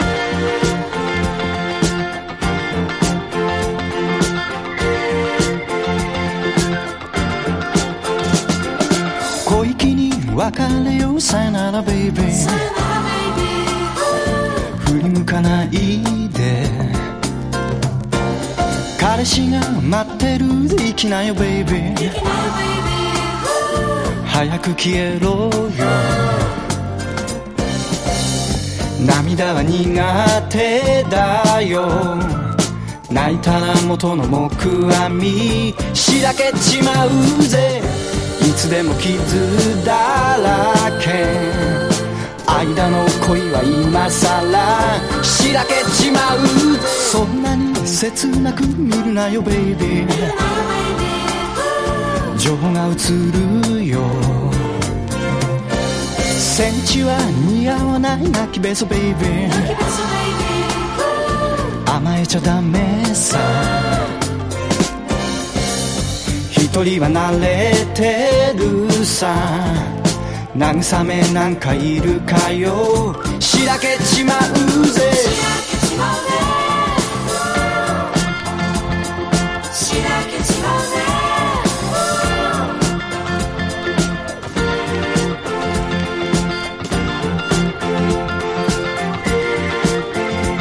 都会的でハードボイルド、粋な男のやるせない心情を歌った日本語ソウル・ミュージックのマスターピース